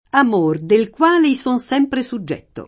am1r del kU#le i S1n S$mpre SuJJ$tto] (Poliziano)